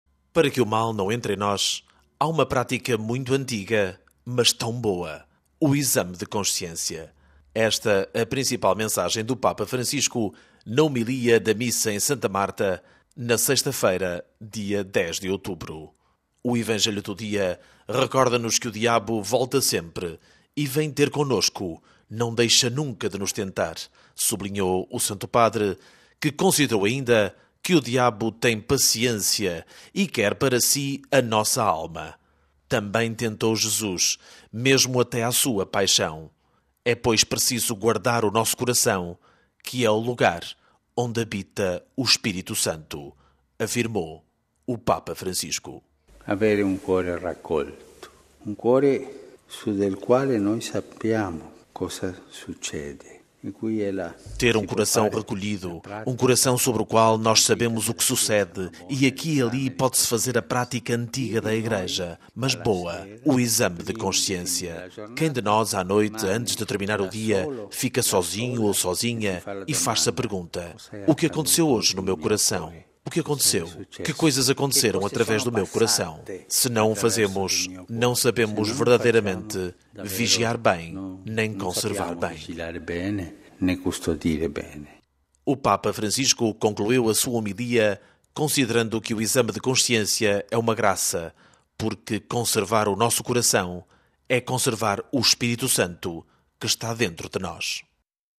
Para que o mal não entre em nós há uma prática muito antiga mas tão boa : o exame de consciência – esta a principal mensagem do Papa Francisco na homilia da Missa em Santa Marta na sexta-feira dia 10 de outubro.